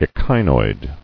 [e·chi·noid]